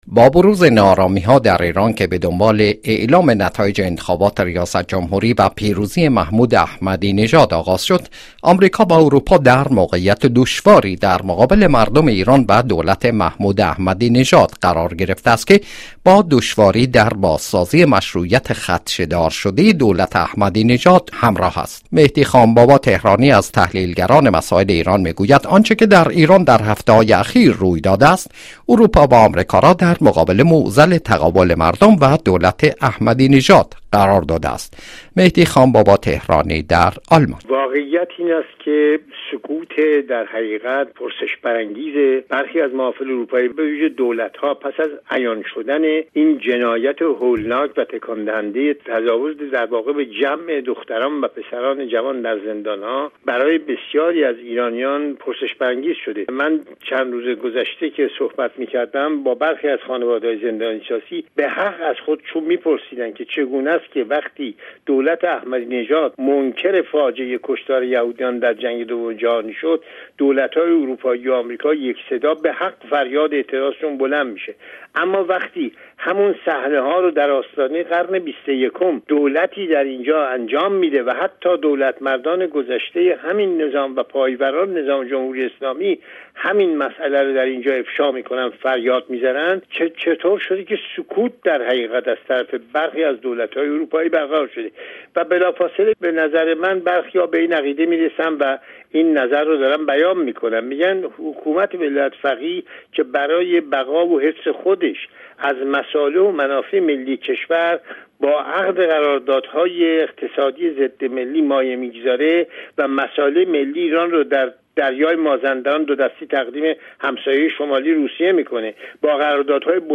گفت و گوی رادیو بین المللی فرانسه